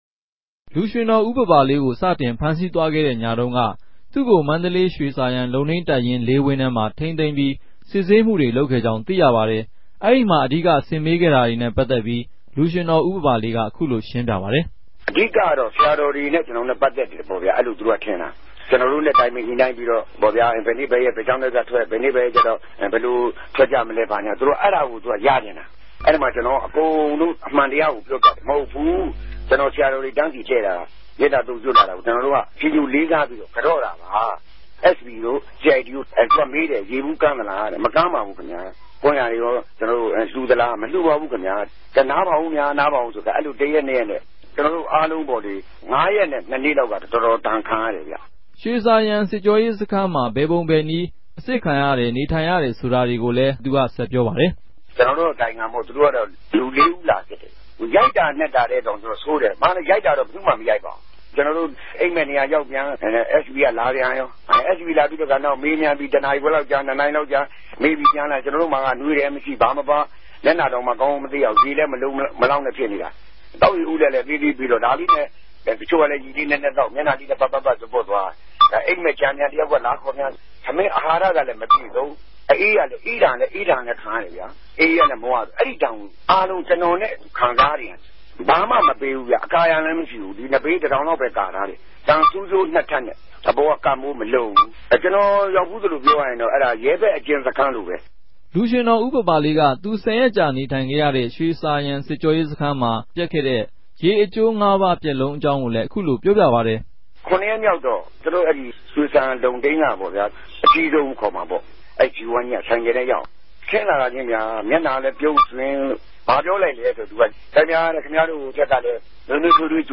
ူပန်လြတ်လာတဲ့ လူ႟ြင်တော် ဦးပၝပၝလေးက သူအထိန်းသိမ်းခံရစဉ် တလနဲႛငၝးရက်တာကာလအတြင်း စစ်ေုကာရေးစခန်းနဲႛ ထောင်တြင်းအတြေႚအုကုံ ၊ ထောင်တြင်းမြာ သူေူပာခဲ့ဆိုခဲ့ဲ့တဲ့ ူပက်လုံးတေနြဲႛပတ်သက်္ဘပီး RFAကို ူပန်ေူပာင်းေူပာူပပၝတယ် ။